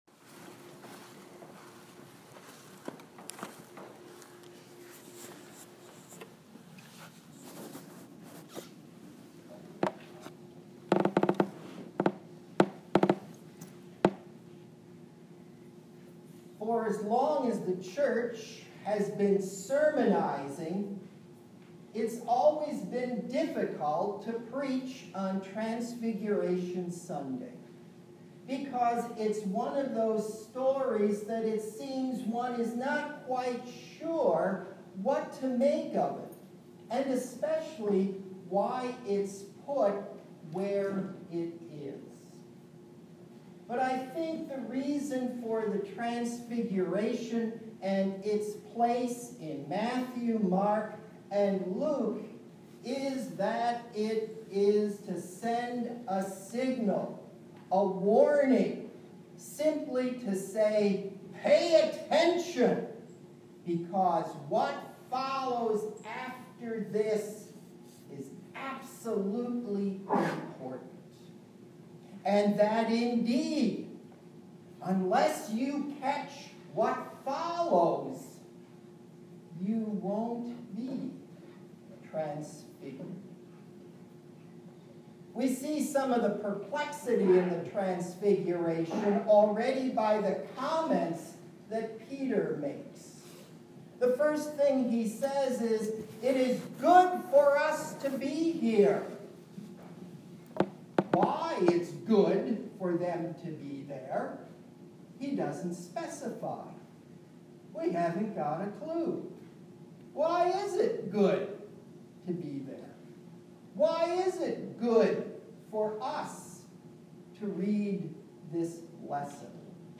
A sermon delivered on February 11/18